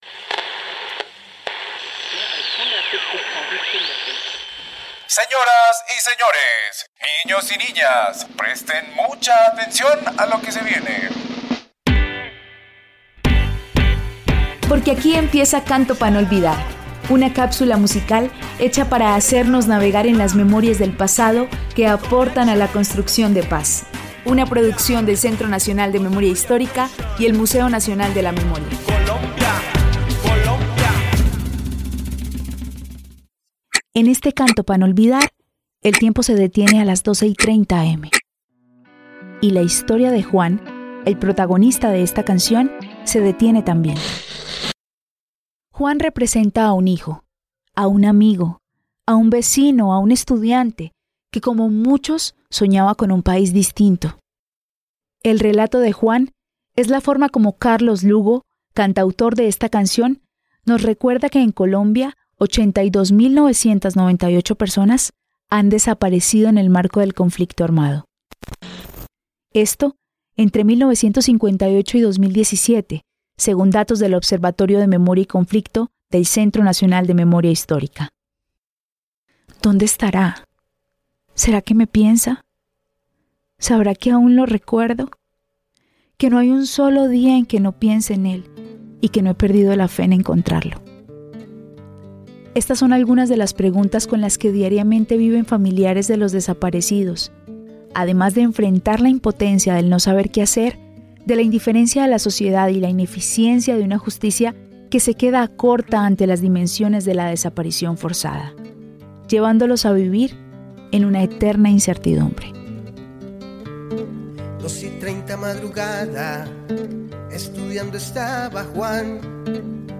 Hace referencia al podcats Tocó cantar (Travesía contra el olvido)12:30 am, (Canción Protesta)